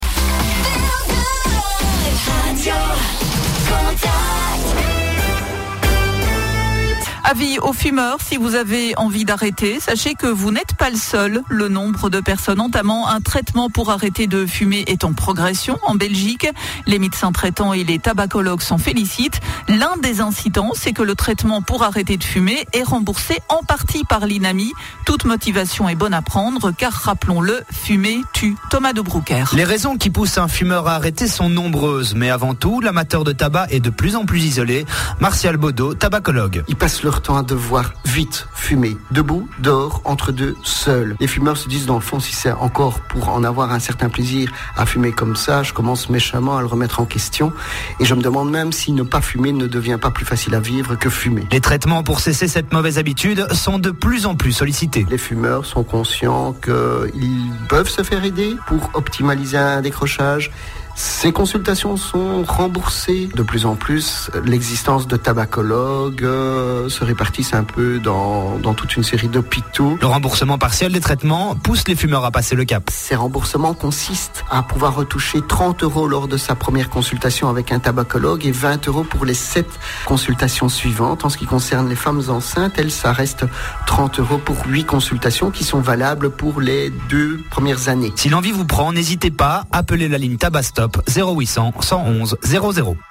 Reportage Radio Contact - Aide aux Fumeurs